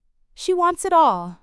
別に早口じゃないですよね。